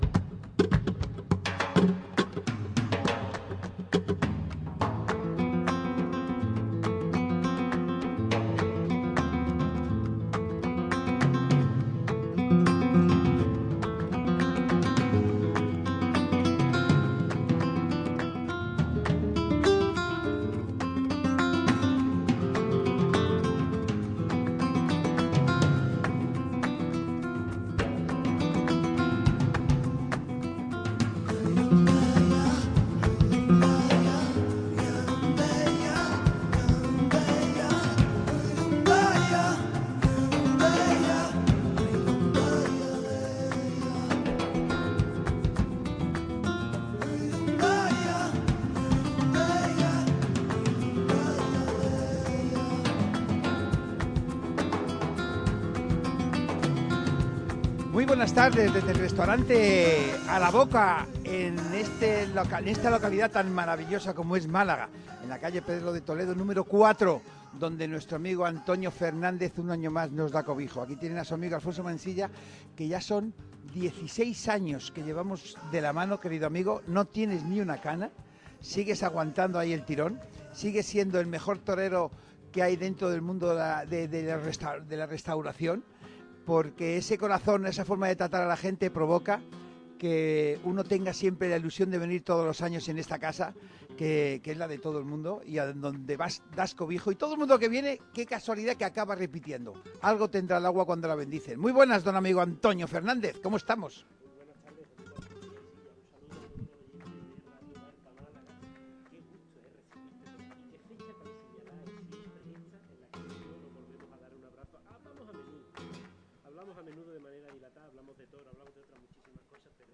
Uno de esos novilleros se ha pasado por el programa de hoy.